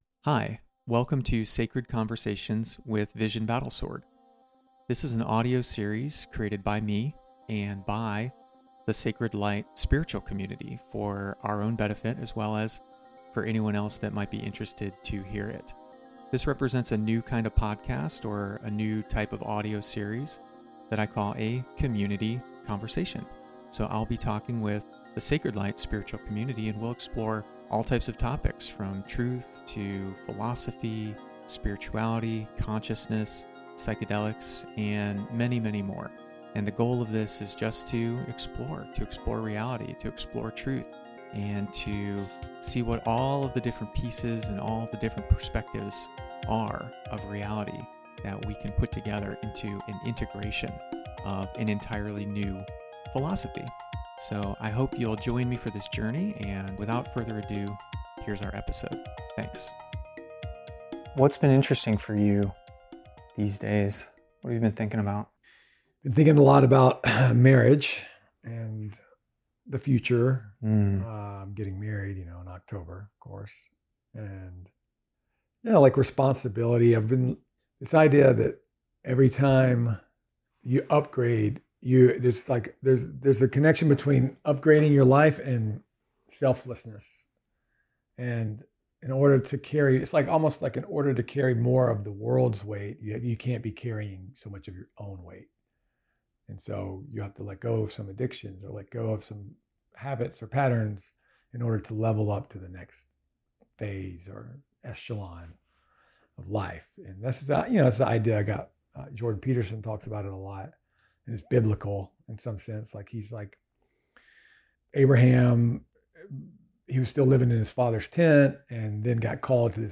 conversation25-addiction.mp3